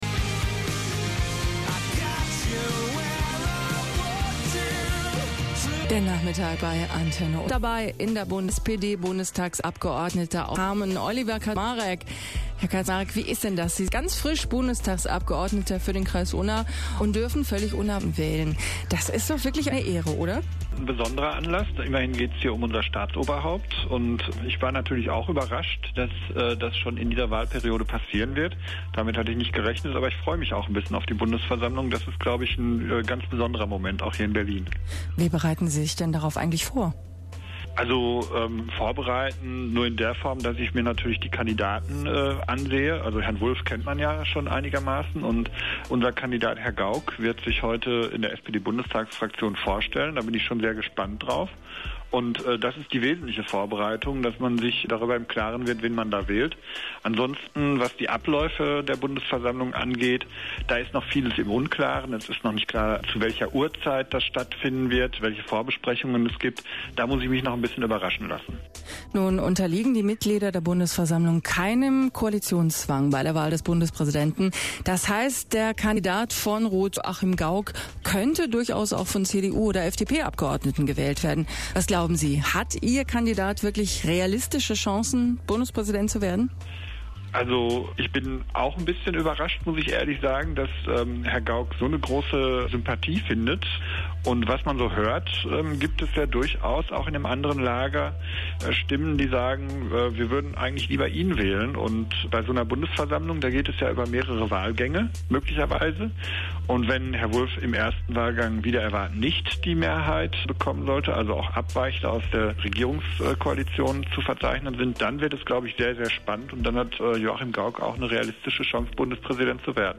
Interview mit Antenne Unna
Der Bundestagsabgeordnete Oliver Kaczmarek wurde am 9. Juni von dem Radiosender Antenne Unna zu der anstehenden Wahl des Bundespräsidenten bzw. der Bundespräsidentin befragt.